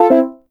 Drone.wav